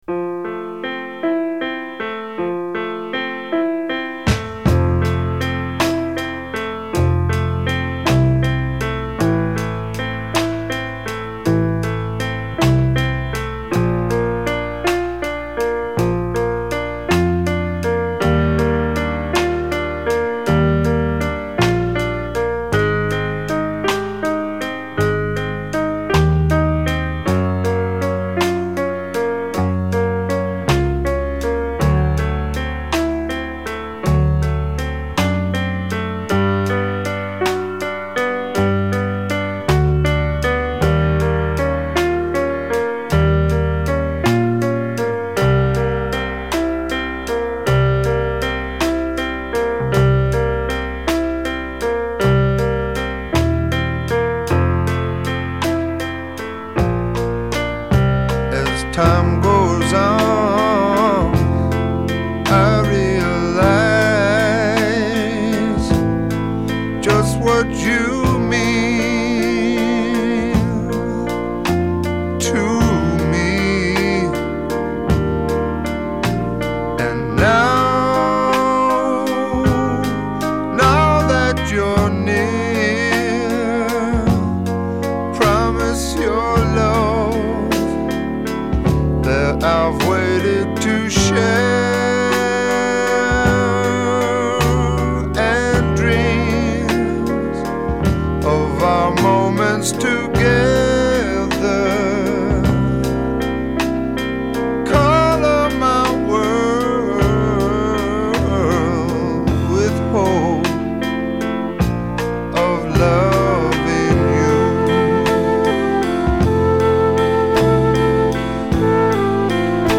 Медляки